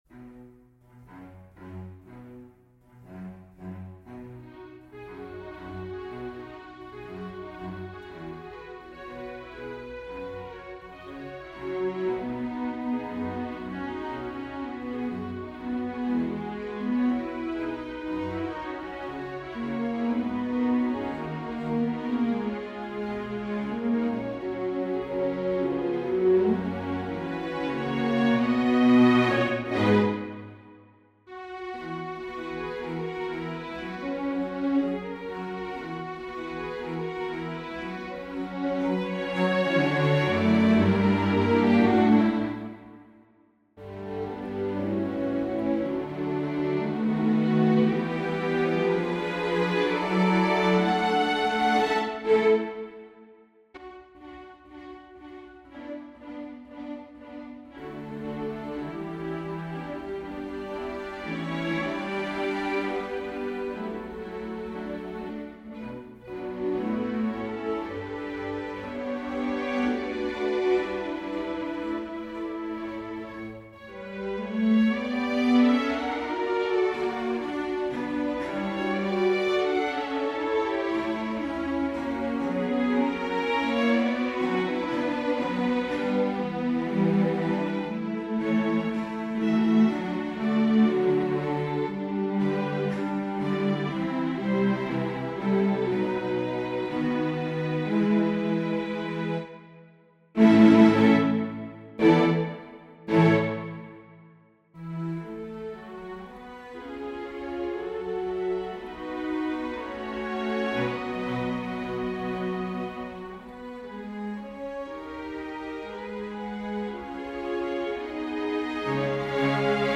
for SATB a cappella choir